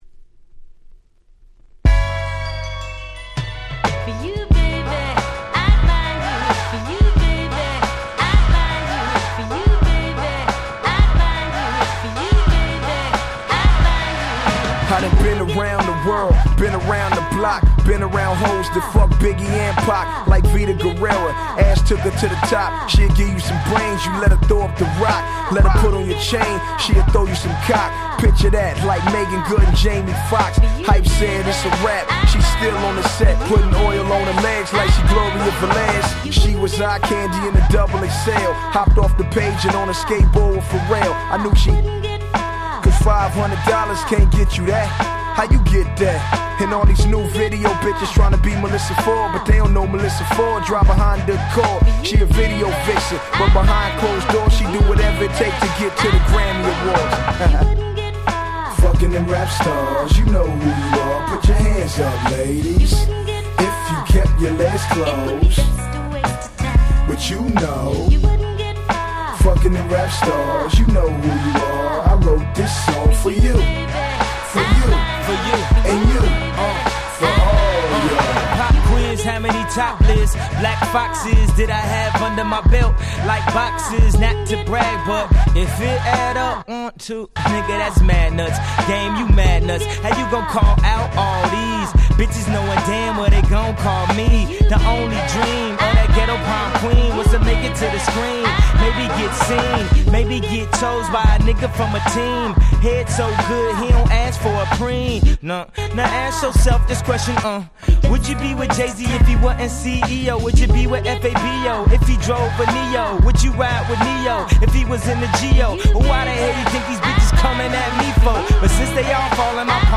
06' Smash Hit Hip Hop !!